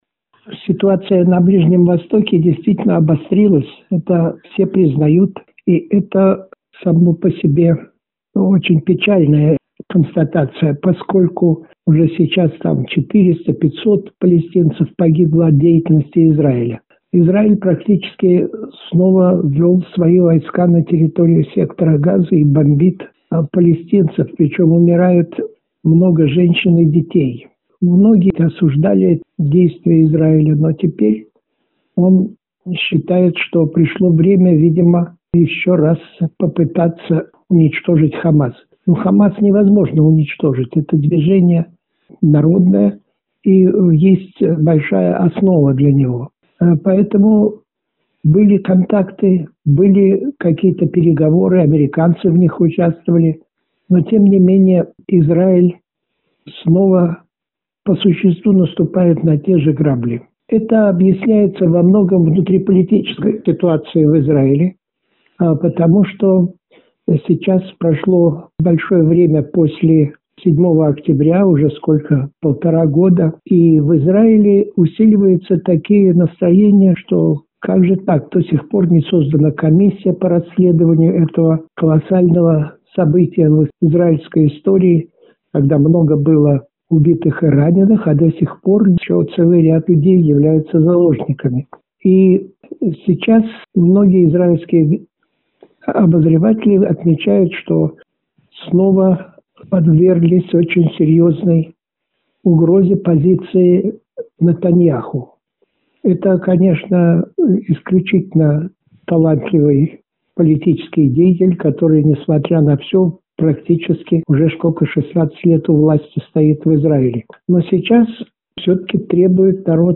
Востоковед, советский и российский дипломат, Чрезвычайный и Полномочный Посол Вениамин Попов в интервью журналу «Международная жизнь» рассказал о ситуации на Ближнем Востоке: